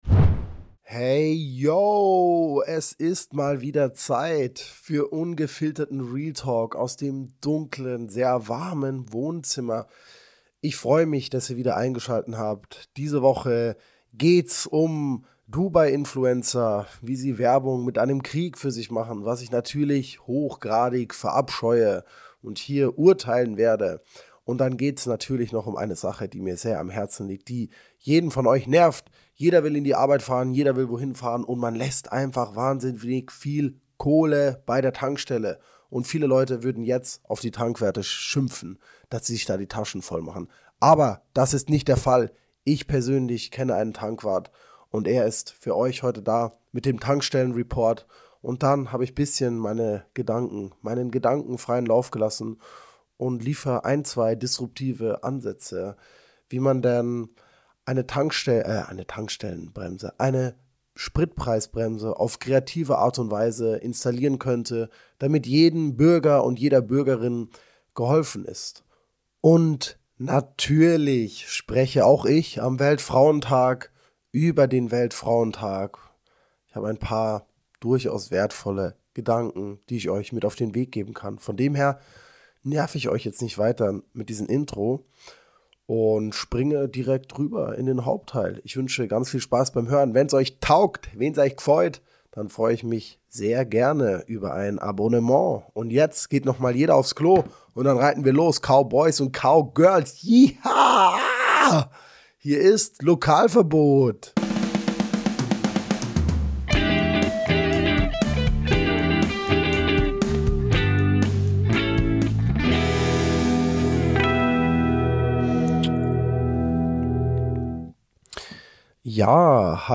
Hierfür habe ich einen örtlichen Tankwart nach seiner Meinung gefragt und er teilt mir uns seine Gefühle!